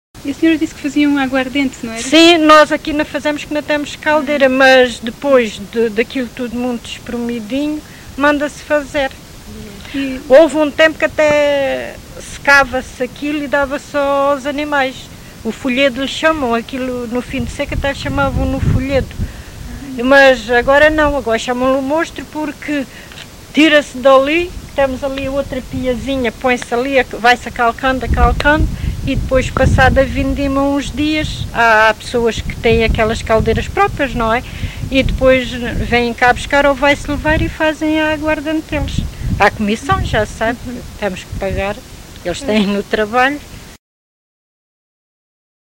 LocalidadeSanta Justa (Coruche, Santarém)